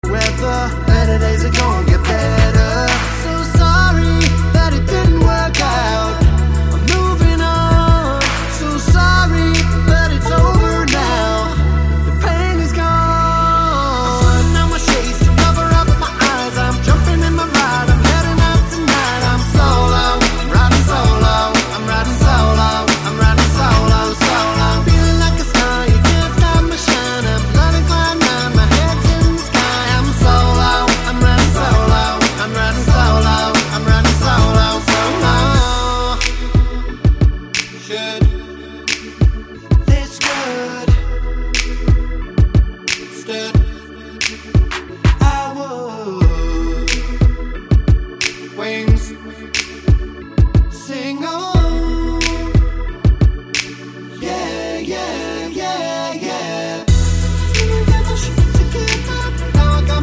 With Lead on V1 and Chorus 1 Pop (2010s) 3:36 Buy £1.50